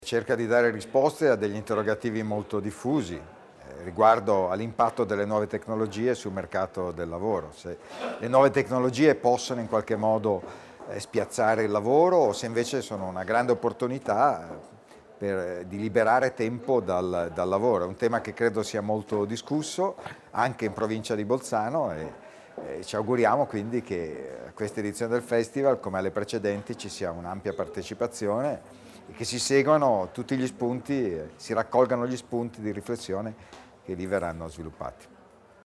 INTV_TITO_BOERI_IT.mp3